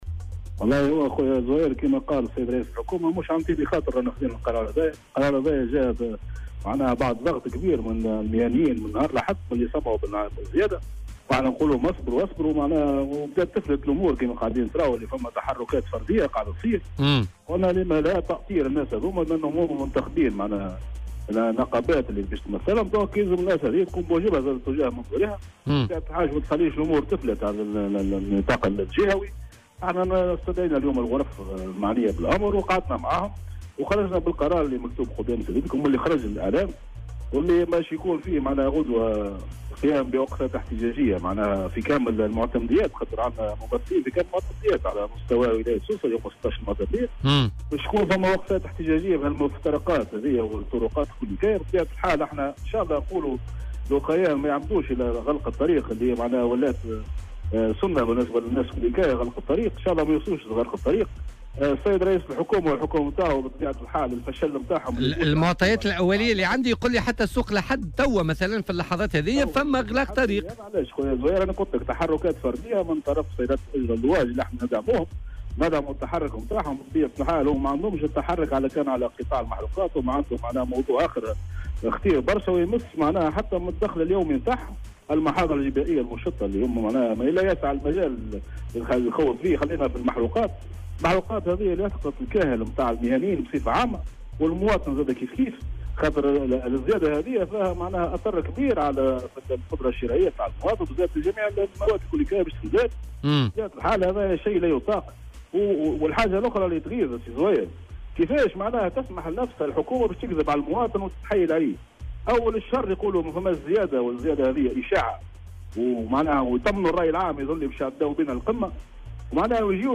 وأضاف في مداخلة له اليوم الثلاثاء في برنامج "بوليتيكا" أنه سيتم تأطير التحركات الاحتجاجية لأصحاب سيارات التاكسي الفردي والجماعي من خلال تنظيم وقفات احتجاجية غدا الأربعاء في كامل معتمديات ولاية سوسة وفي مفترق الطرقات انطلاقا من الساعة السابعة صباحا إلى غاية منتصف النهار.